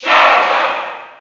File:Charizard Cheer English SSB4.ogg